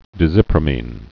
(dĭ-zĭprə-mēn, dĕzə-prămĭn)